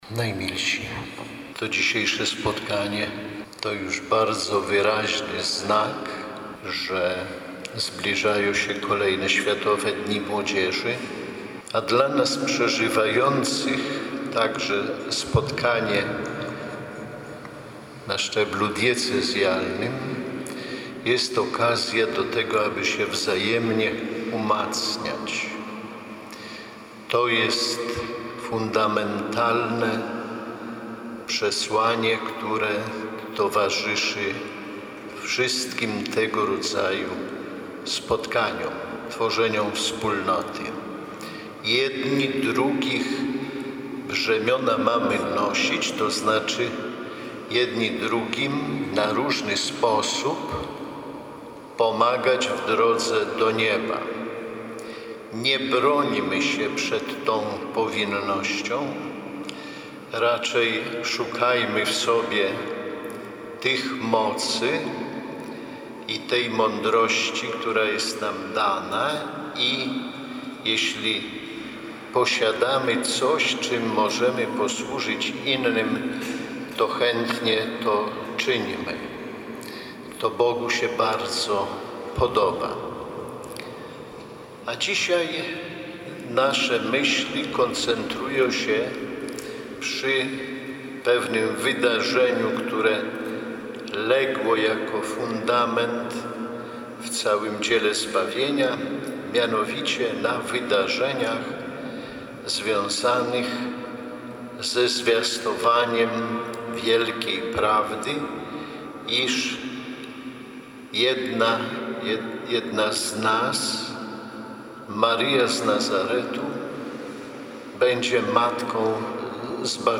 Ordynariusz diecezji warszawsko-praskiej przewodniczył Liturgii Słowa z obrzędem posłania na Światowe Dni Młodzieży.
Biskup Romuald Kamiński spotkał się z młodymi diecezji warszawsko-praskiej w Bazylice Najświętszego Serca Pana Jezusa przy ul. Kawęczyńskiej w Warszawie. Duchowny mówił o tym, jak ważne jest wypełniane woli Boga w życiu każdego człowieka. Wskazał na Maryję, która jest tego przykładem.